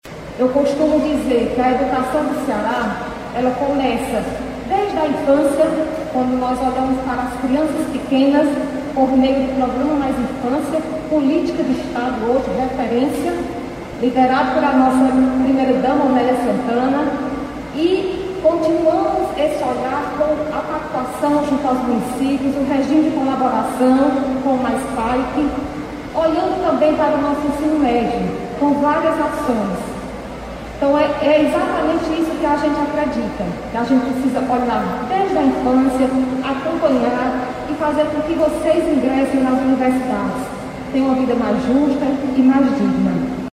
A secretária da Educação, Eliana Estrela, reforçou que, apoiados pelo Governo do Ceará, Seduc e escolas, os estudantes cearenses têm potencial para conquistarem o que desejam.